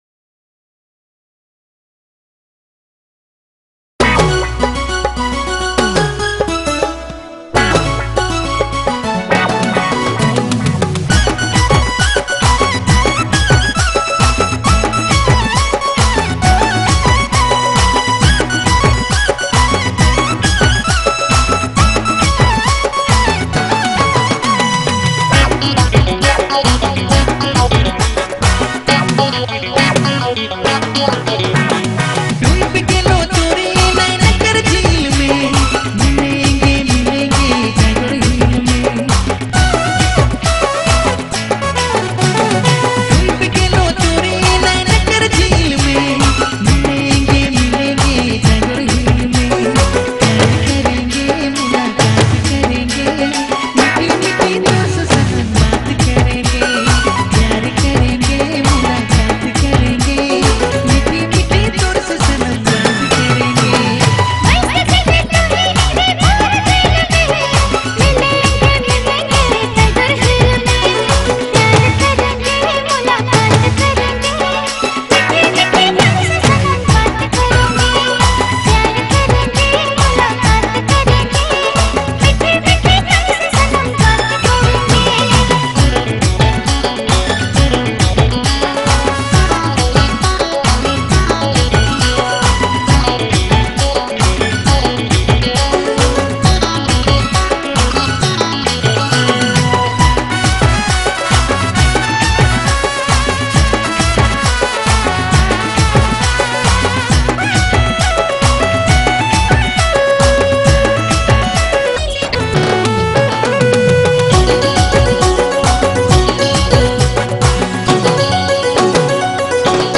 dynamic Nagpuri DJ remix